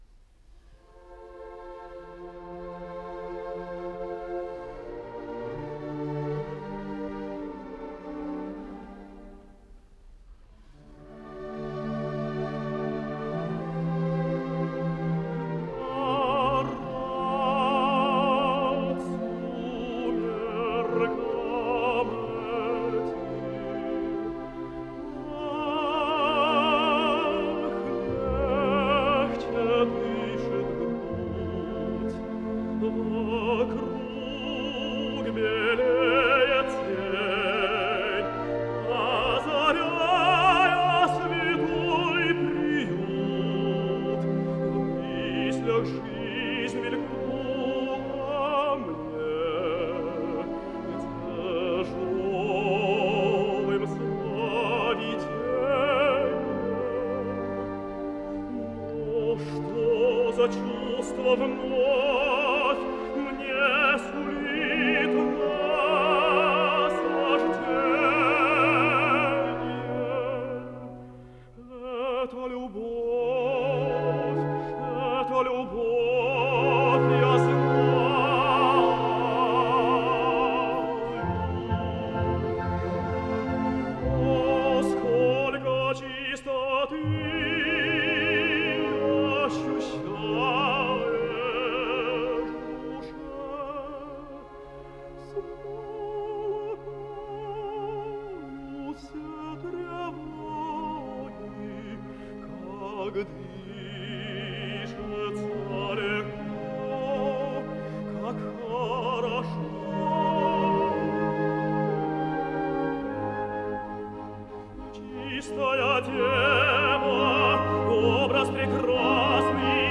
Драматическая легенда для солистов, хора и оркестра, соч. 24 Гектор Берлионов